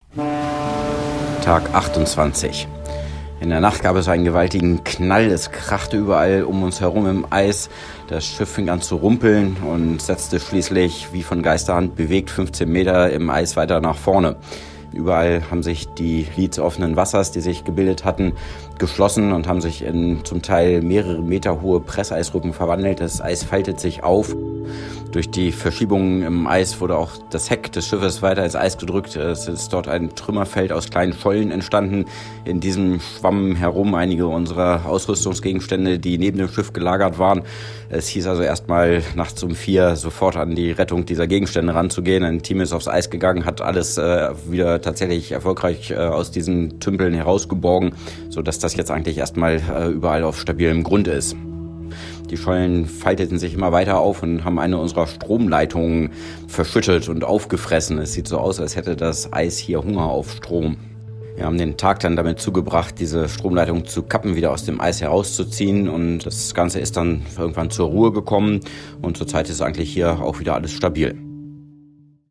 Eispressung – eine Eisscholle donnert in uns rein
Position 84°45’N 133°25’E
Ich bin durch einen gewaltigen Schlag auf den Schiffskörper aufgewacht. Polarstern hat dann stark vibriert und sich geschüttelt. Das Ganze von einem lauten kratzenden Geräusch untermalt.